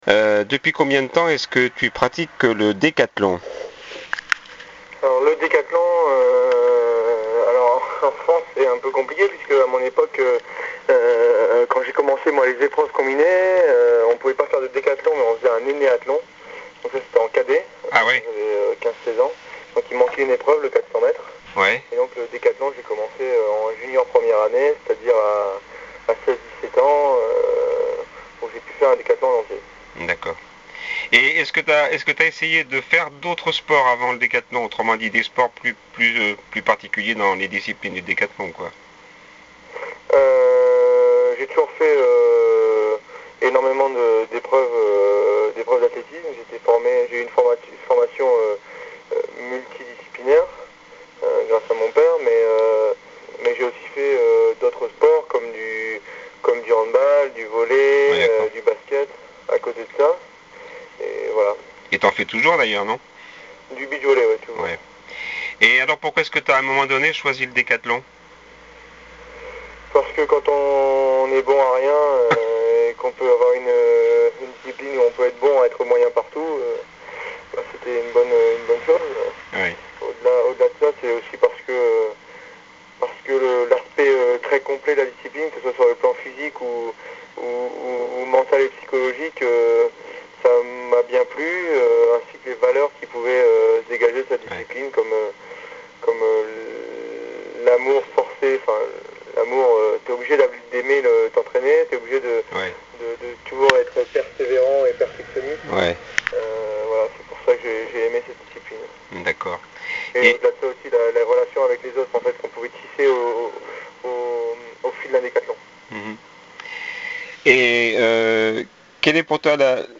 Interview with Romain Barras, Talence 2010 (0)
interview-romain-barras-talence-2010.mp3